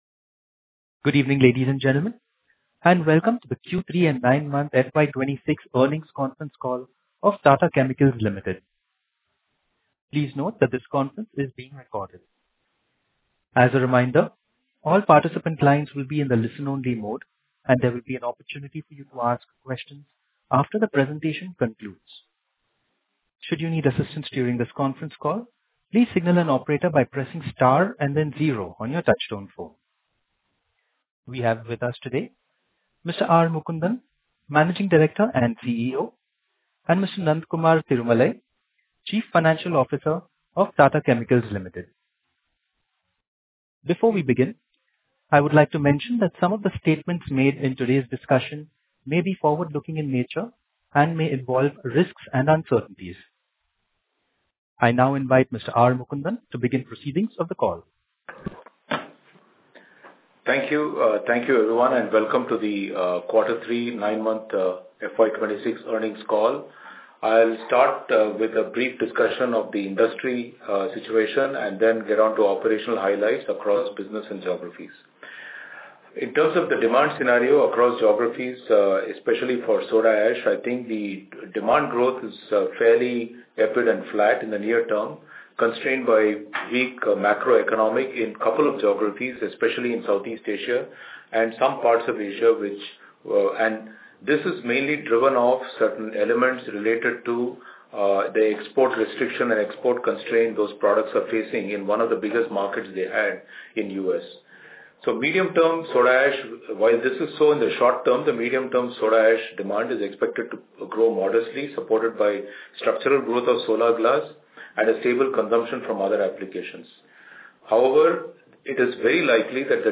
Investor Call Audio Recording